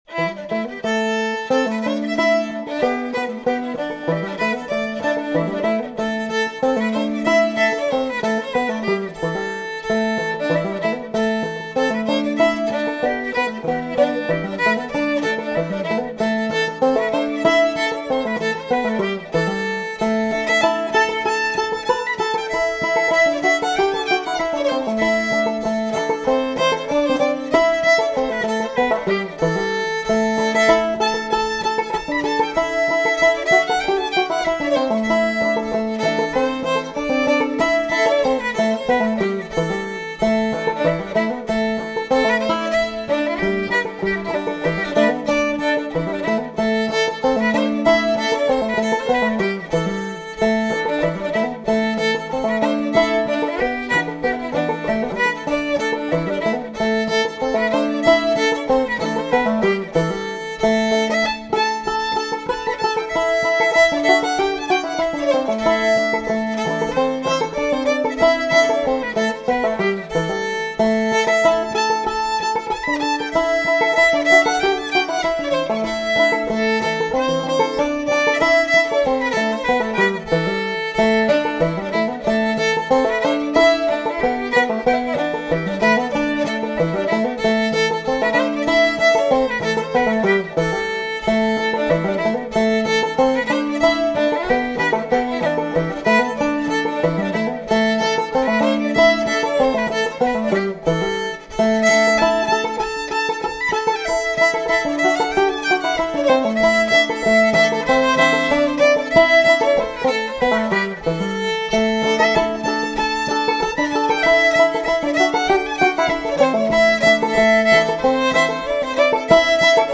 Their fiddle and banjo playing has a lovely, intertwined sound where the "accents accent each other....bouncy, rhythmic, lyrical and expressive..."
fiddle
banjo